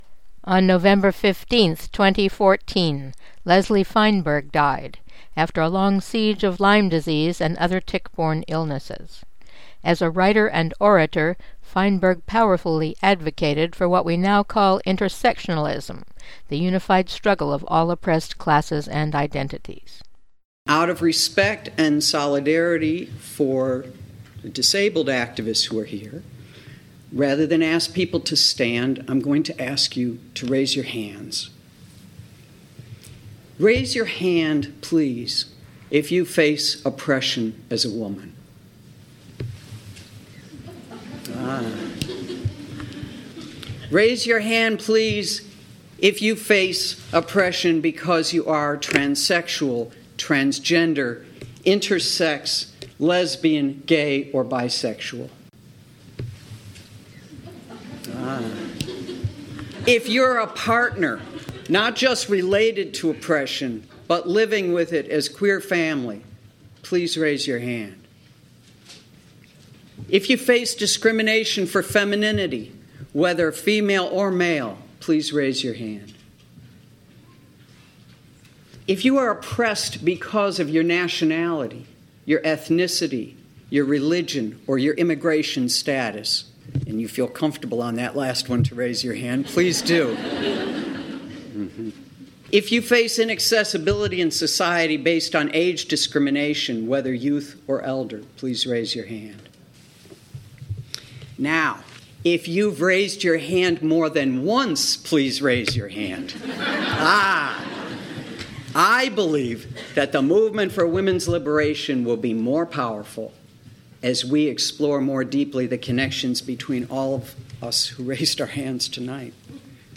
Part one of speech by the late transgender feminist